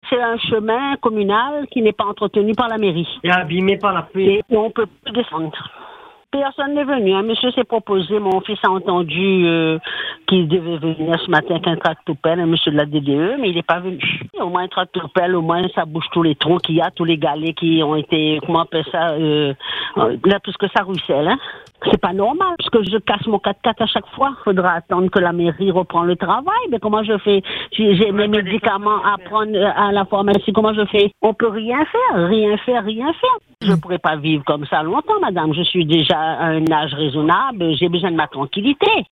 À Bois Rouge, à Sainte-Marie, non loin de la route de Bellevue, une habitante pousse un coup de gueule contre l’état d’un chemin communal manifestement laissé à l’abandon par la mairie.